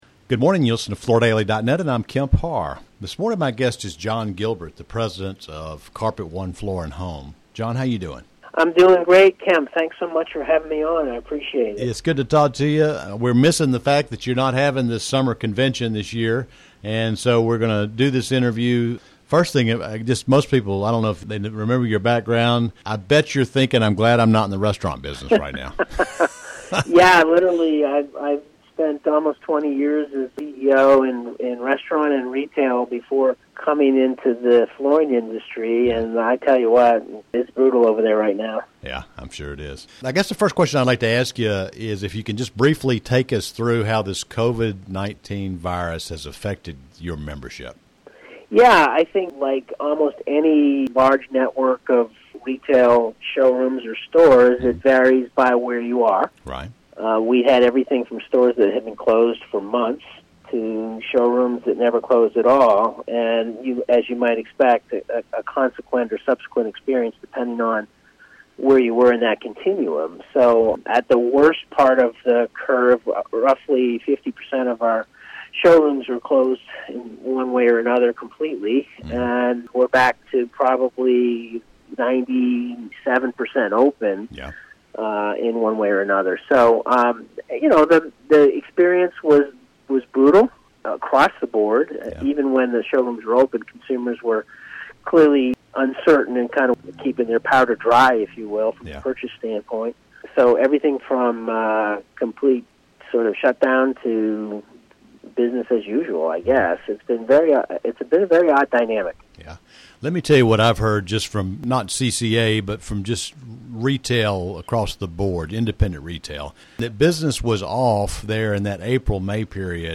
Listen to the interview for more details as well as a quick summary of the group's virtual summer meeting called CCA Lift 2020.